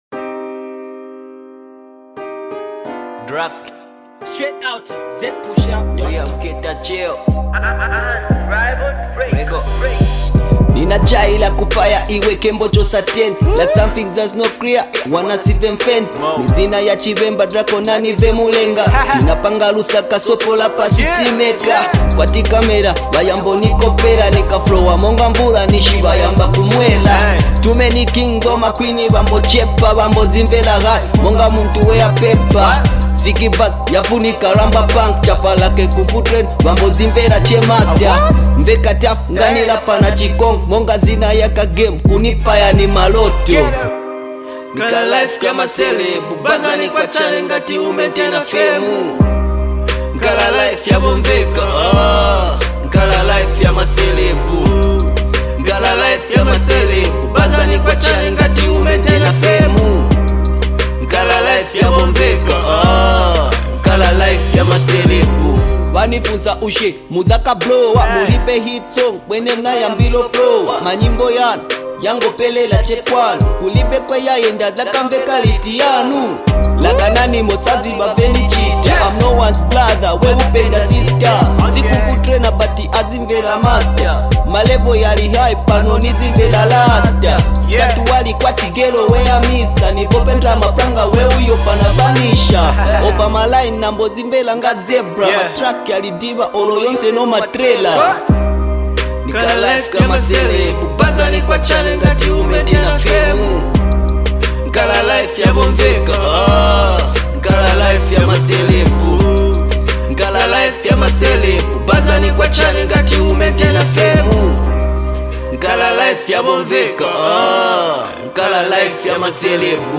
on a beast instrumental